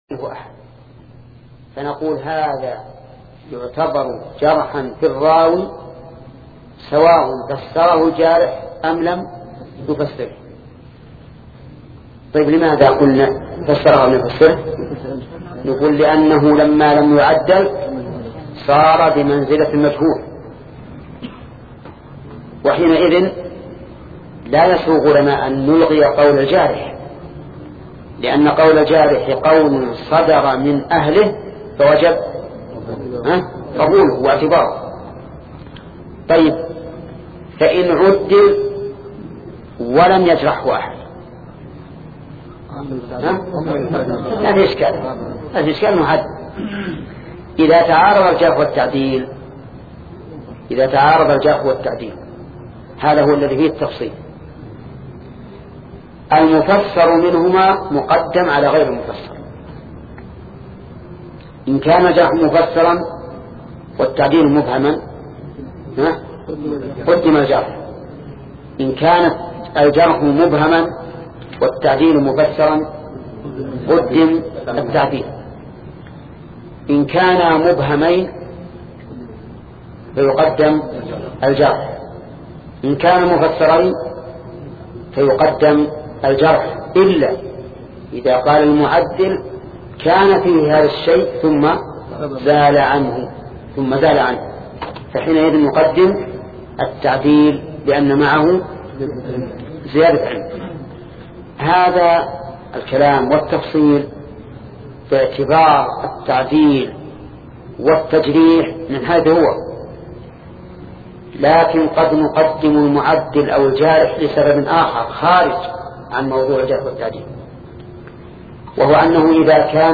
شبكة المعرفة الإسلامية | الدروس | شرح نخبة الفكر (16) |محمد بن صالح العثيمين